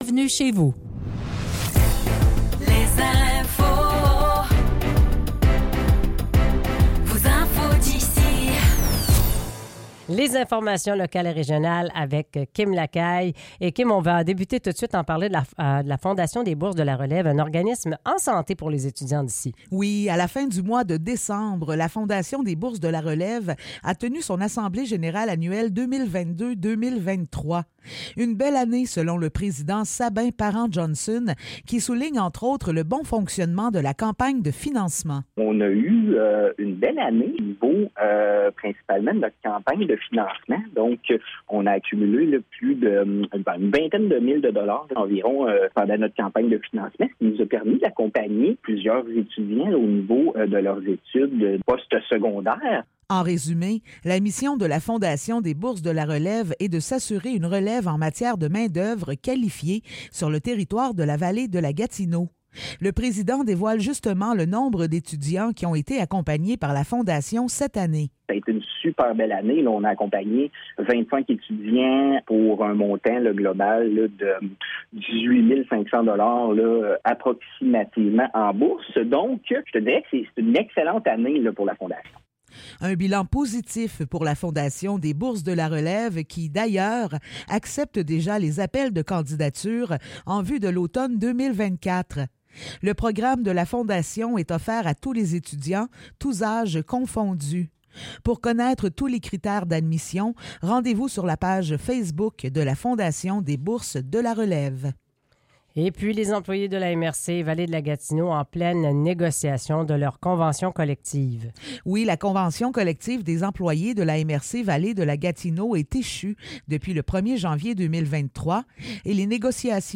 Nouvelles locales - 17 janvier 2024 - 8 h